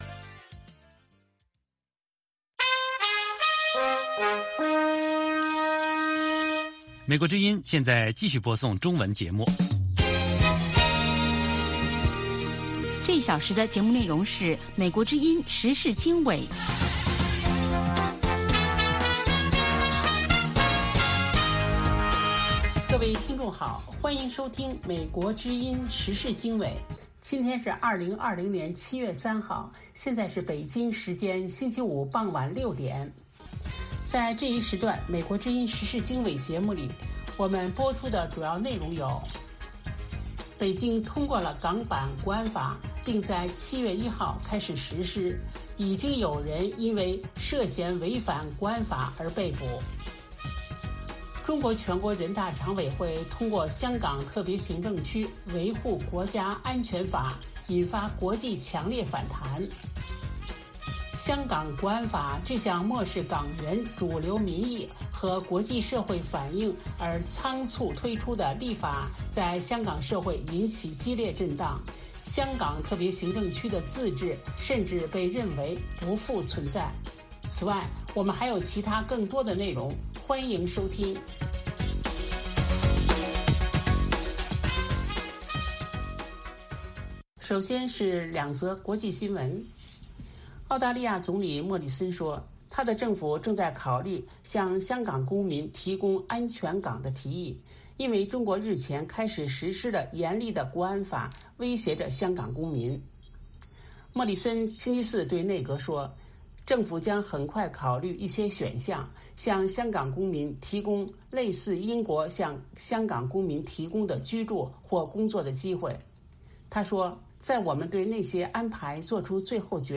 北京时间下午5-6点广播节目。广播内容包括美语训练班(学个词， 美国习惯用语，美语怎么说，英语三级跳， 礼节美语以及体育美语)，以及《时事大家谈》(重播)